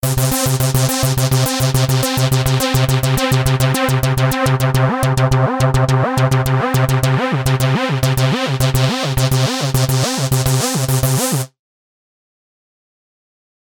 The next example demonstrates the usage of the glide function. The track length is reduced to 4 steps. Following notes are played with a fixed gatelength of 92%:
Finally glide will also be activated on the third step: